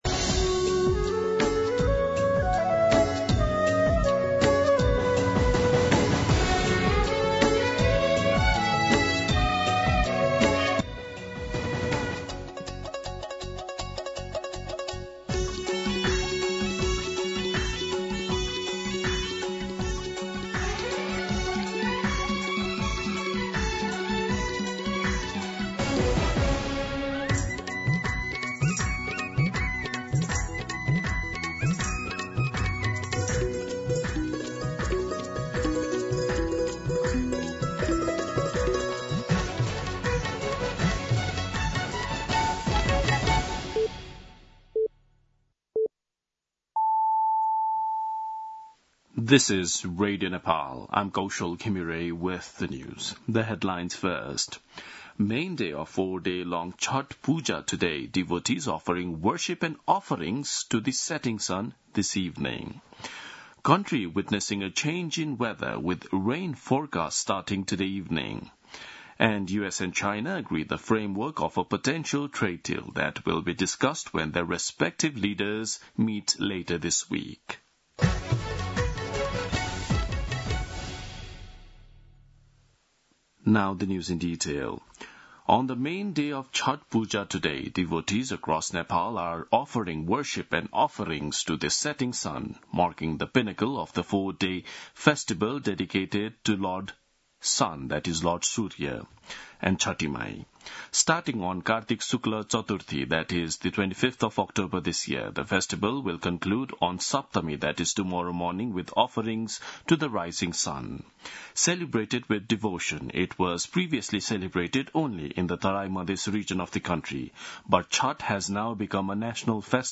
दिउँसो २ बजेको अङ्ग्रेजी समाचार : १० कार्तिक , २०८२
2-pm-English-News-11.mp3